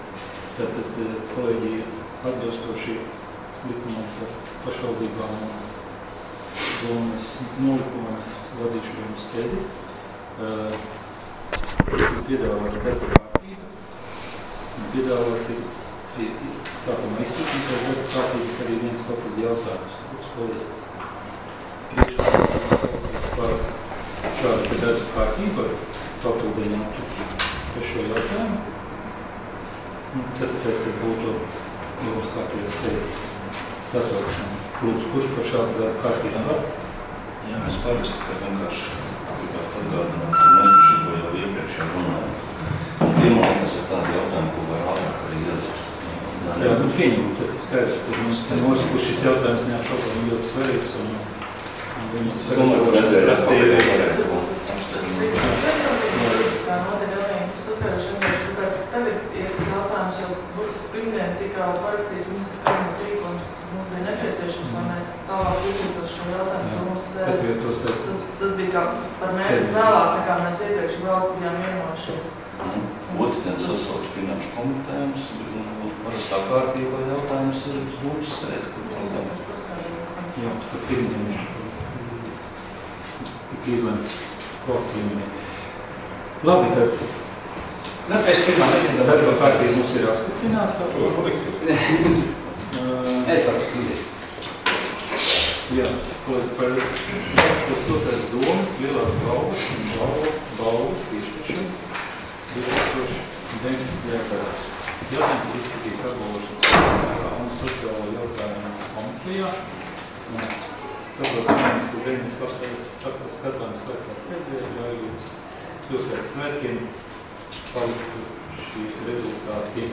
Domes sēdes 26.07.2019. audioieraksts